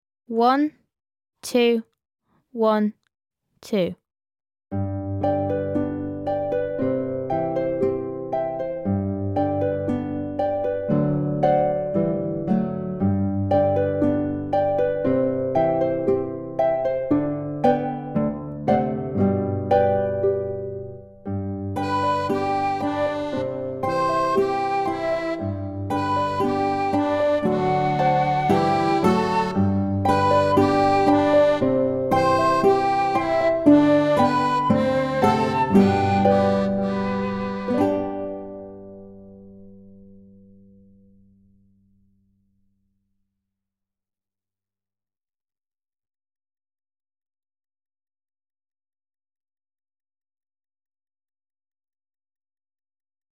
34. Harpist's Fingers (Backing Track)